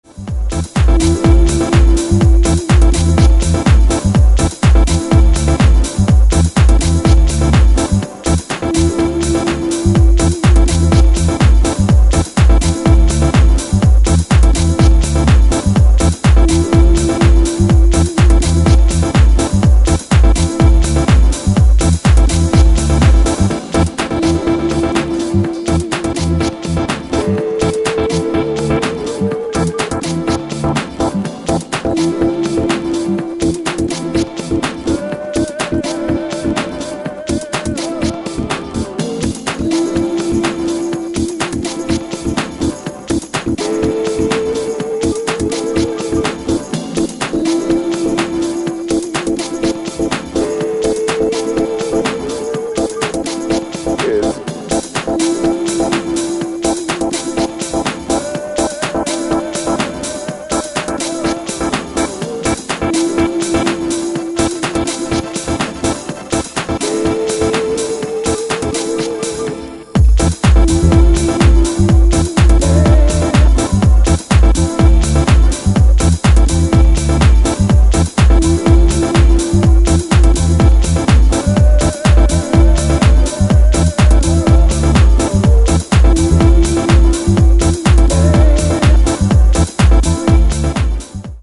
ジャンル(スタイル) DISCO HOUSE / DEEP HOUSE / RE-EDIT